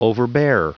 Prononciation du mot overbear en anglais (fichier audio)
Prononciation du mot : overbear